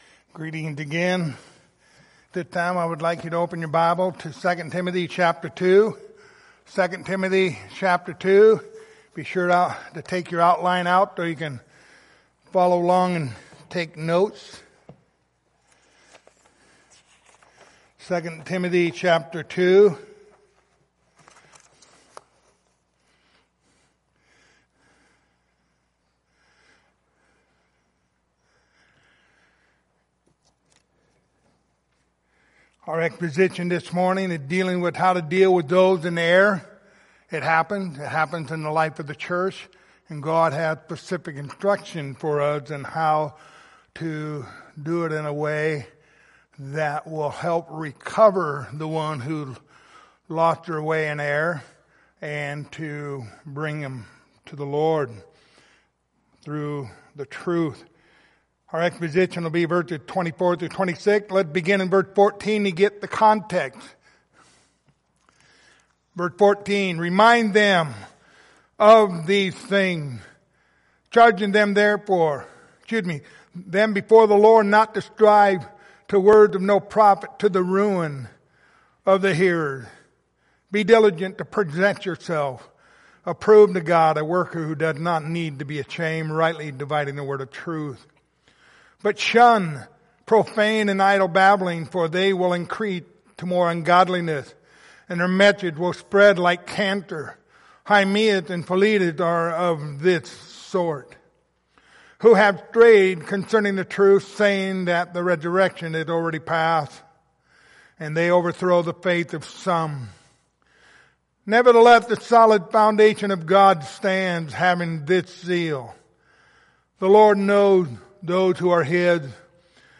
Pastoral Epistles Passage: 2 Timothy 2:24-26 Service Type: Sunday Morning Topics